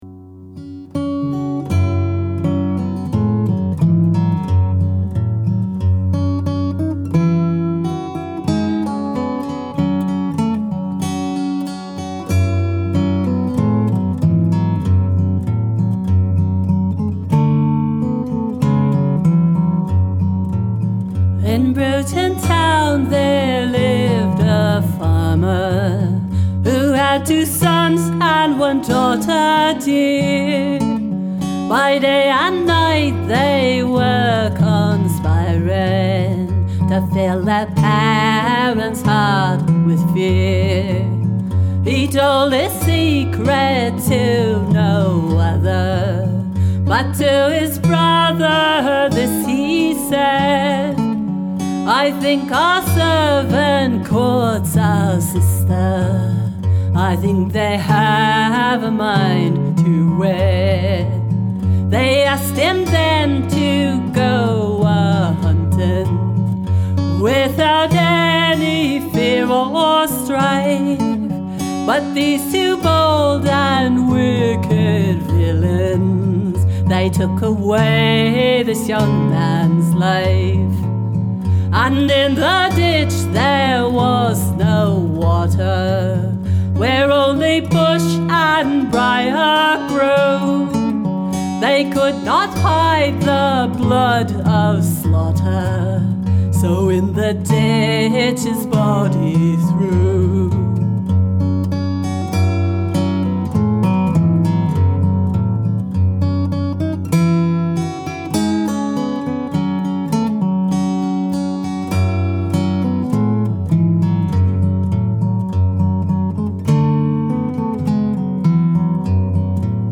All tracks traditional.
indeed: both exuberantly intricate and
understated, gentle and yet percussive, not